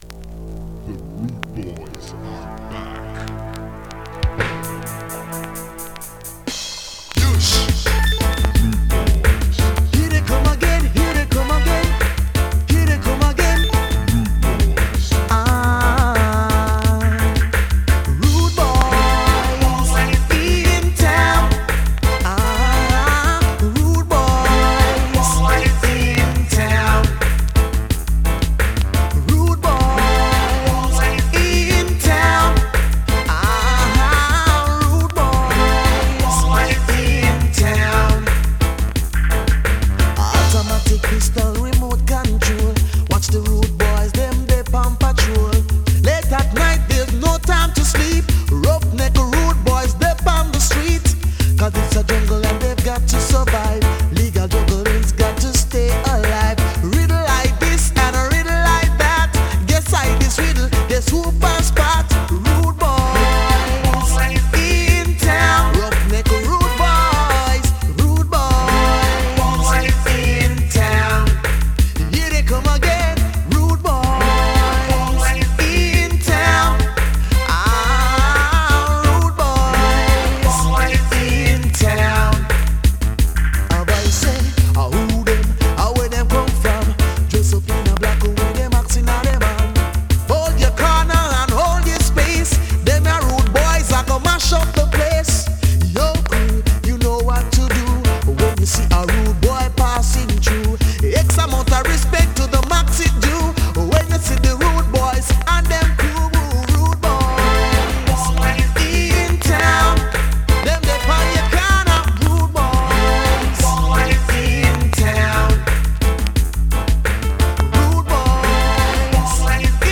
ホーム > 2021 NEW IN!! DANCEHALL!!
スリキズ、ノイズそこそこありますが